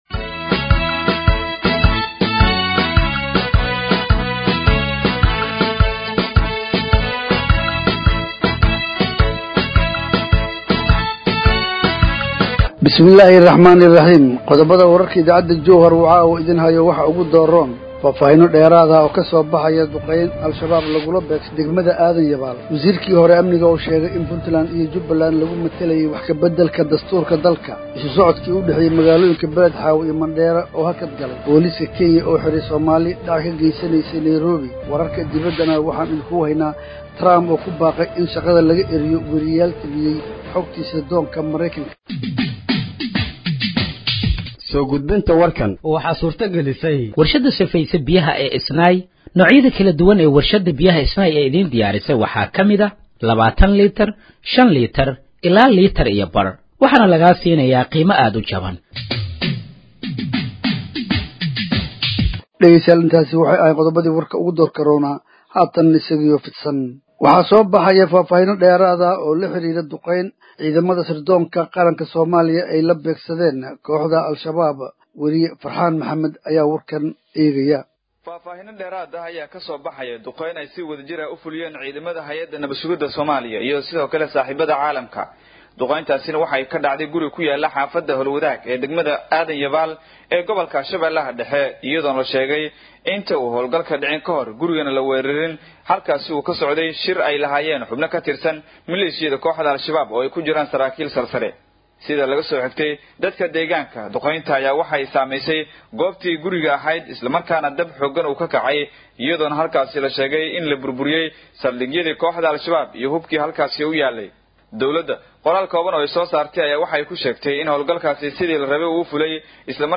Dhageeyso Warka Habeenimo ee Radiojowhar 27/06/2025
Halkaan Hoose ka Dhageeyso Warka Habeenimo ee Radiojowhar